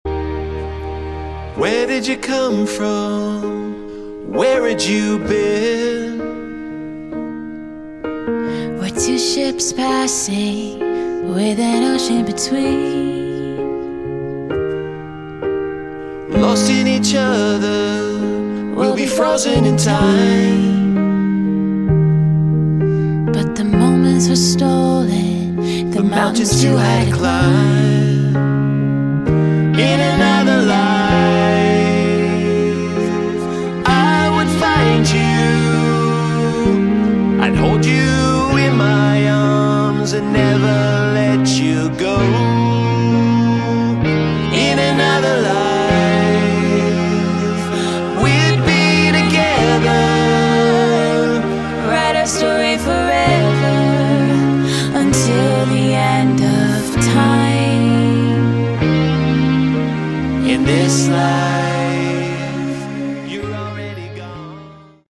Category: Melodic Hard Rock
vocals
bass
guitars
keyboards
drums